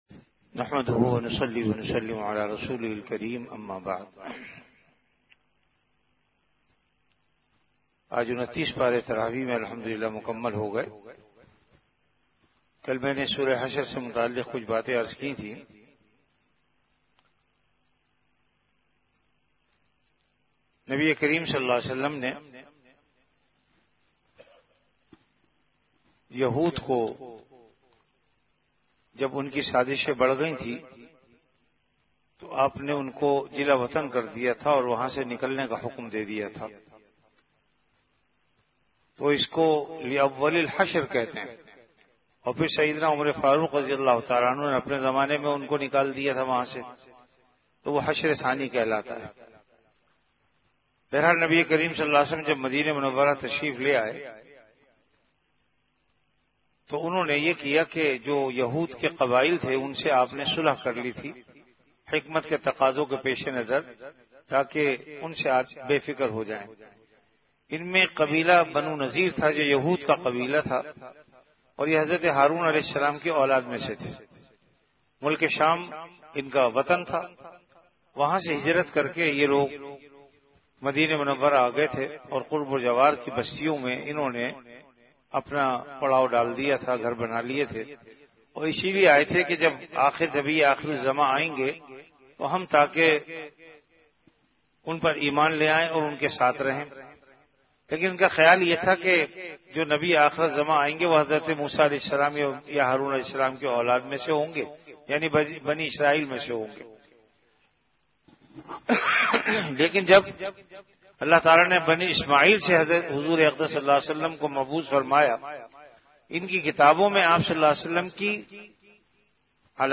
An Urdu Islamic audio lecture on Ramadan - Taraweeh Bayan, delivered at Jamia Masjid Bait-ul-Mukkaram, Karachi.
Ramadan - Taraweeh Bayan · Jamia Masjid Bait-ul-Mukkaram, Karachi